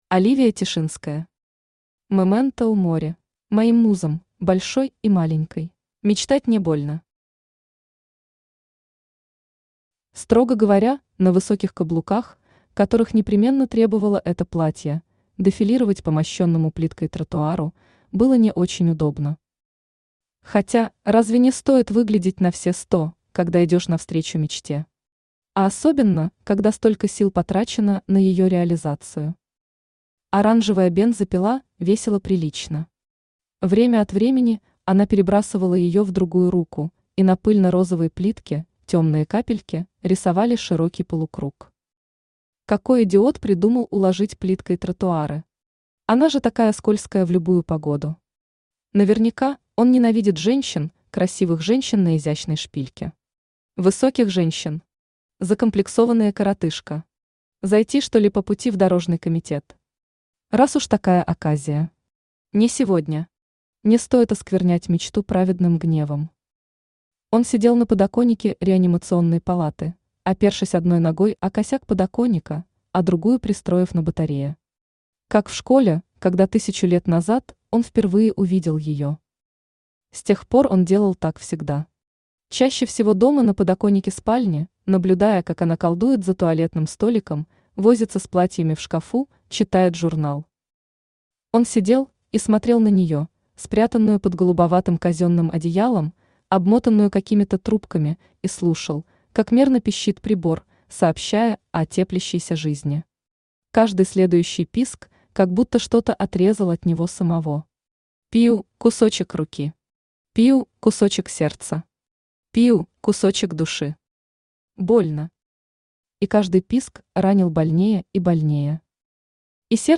Aудиокнига Memento mori Автор Оливия Тишинская Читает аудиокнигу Авточтец ЛитРес.